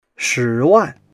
shi2wan4.mp3